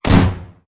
EXPLOSION_Arcade_11_mono.wav